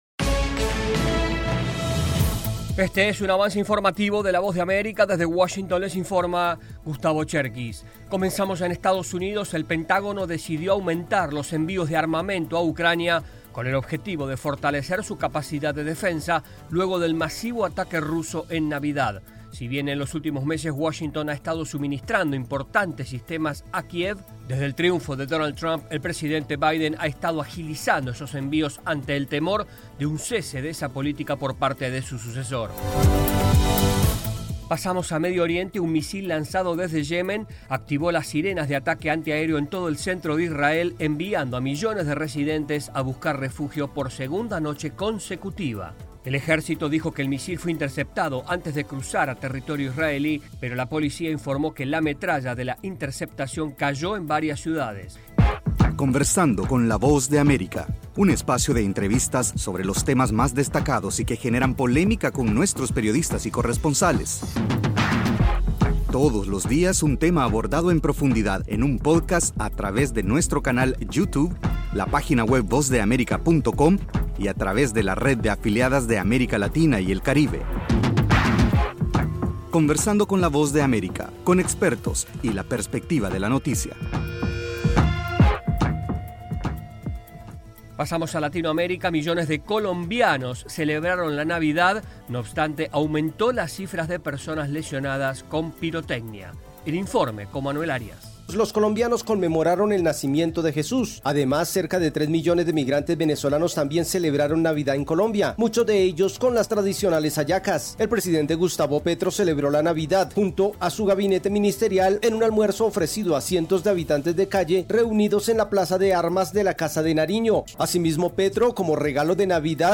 El siguiente es un avance informativo de la Voz de América.
["Avance Informativo" es un segmento de noticias de la Voz de América para nuestras afiliadas en la región de América Latina y el Caribe].